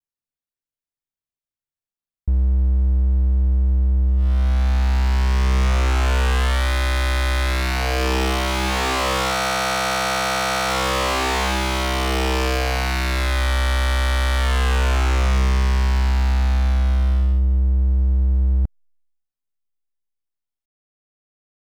Fetter Sound. klingt irgendwann genau wie Minibrute.
Hier mal der Microbrute in Action und die Wellenform, wenn man voll aufdreht, Filter offen.